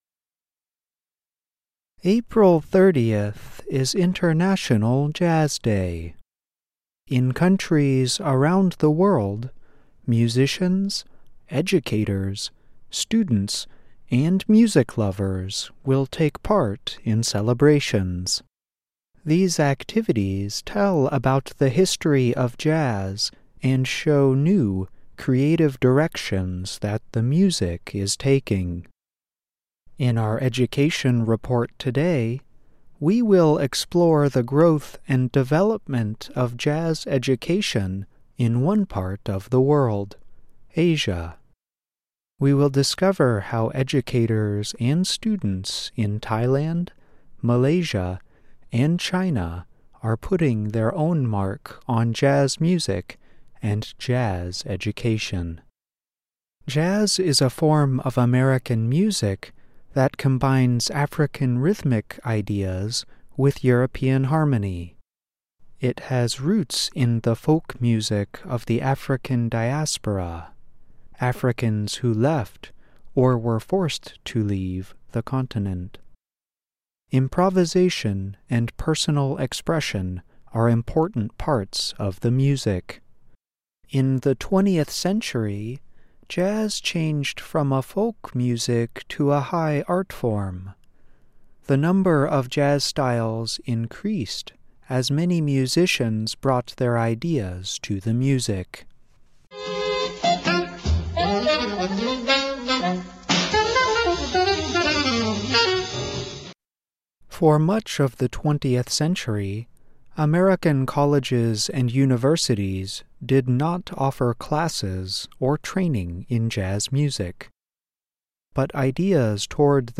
In today's report, we explore jazz education in Asia -- Thailand, Malaysia, and China, in particular. We discover how some educators and students are making their own mark on the music.